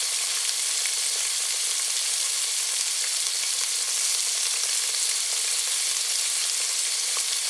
SFX_pan_sizzle_loop.wav